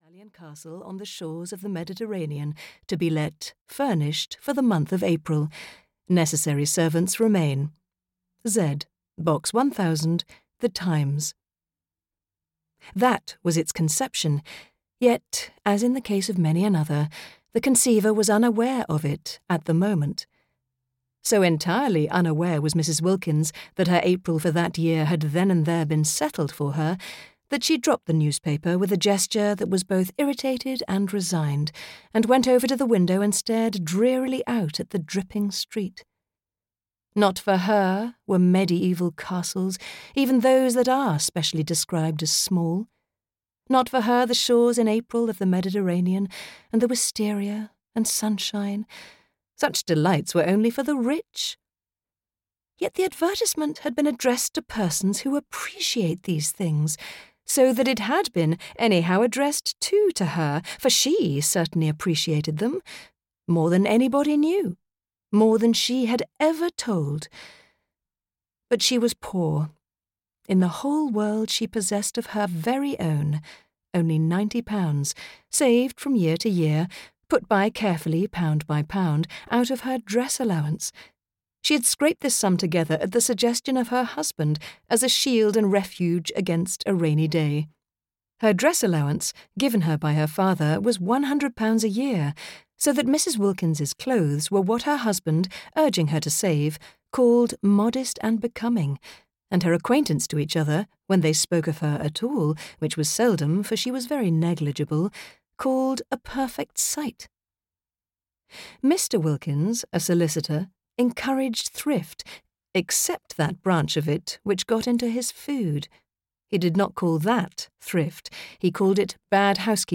The Enchanted April (EN) audiokniha
Ukázka z knihy